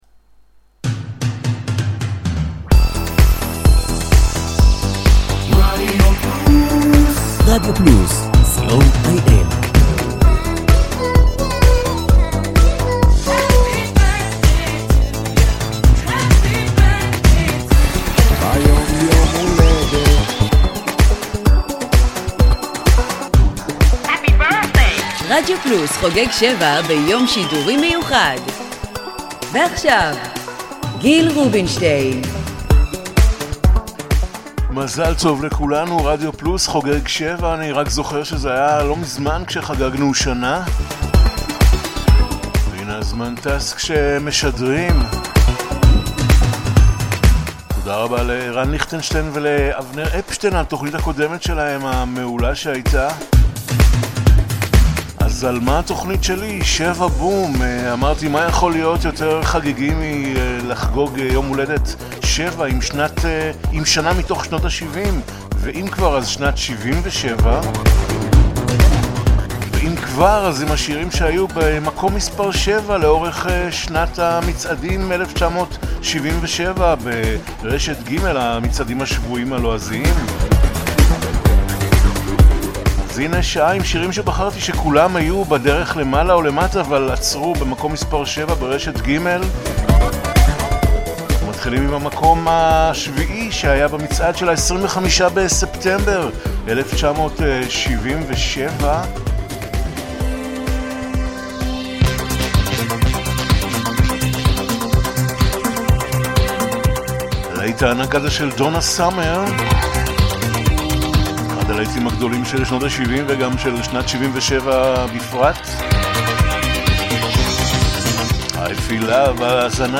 a weekly 80's music show